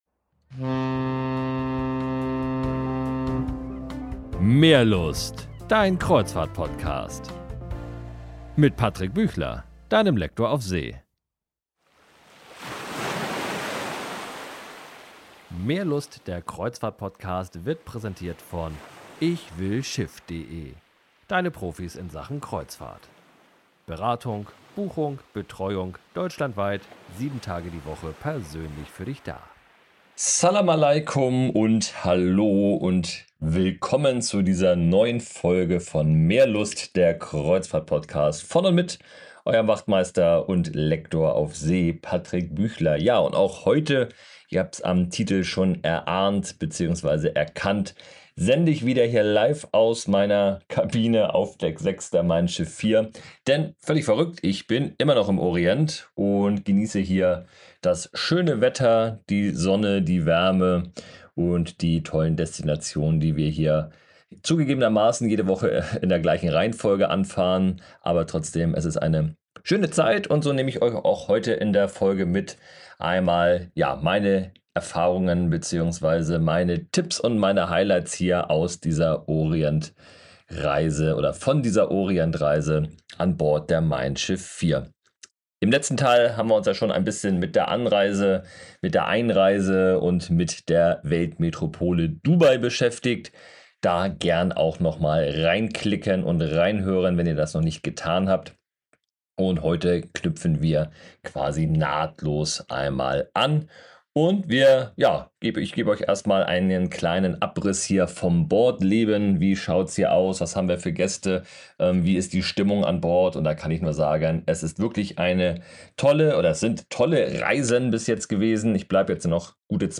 #38 Live von Bord Orient Teil 2
Abu Dhabi und der Oman - direkt live von Bord